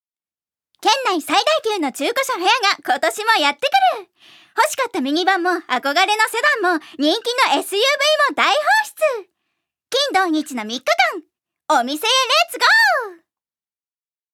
預かり：女性
音声サンプル
ナレーション１